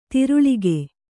♪ tiruḷige